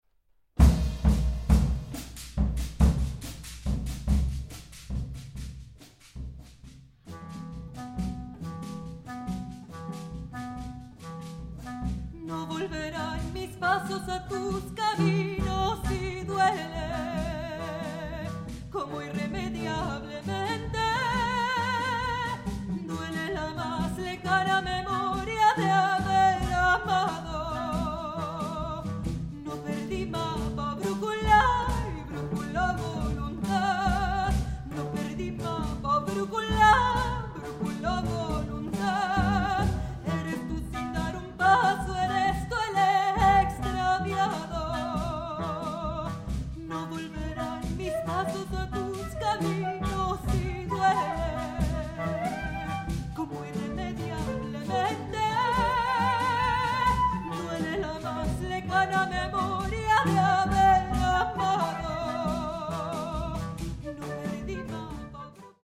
soprano
tenor
flute
clarinet
cello